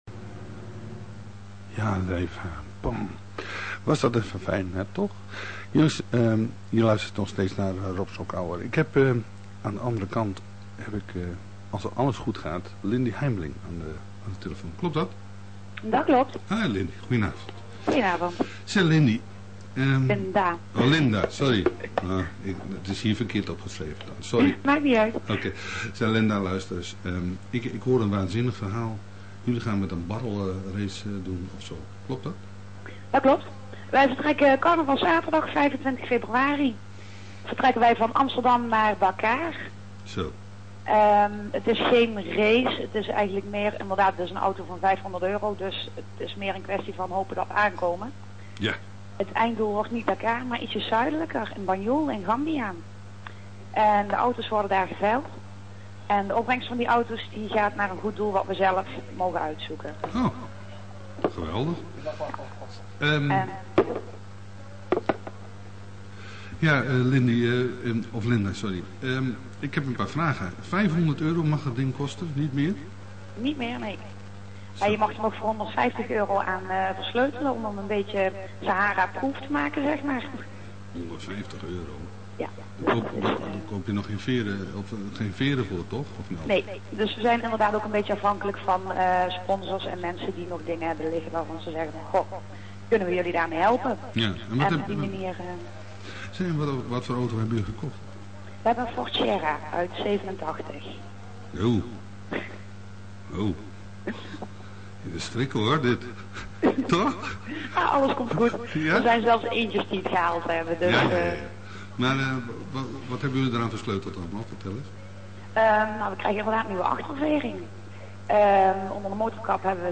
Het kwam nogal onverwacht en we hebben het helaas alleen snel kunnen opnemen op een cassette bandje...
VOX_interview_radio_18-1_64Kb.mp3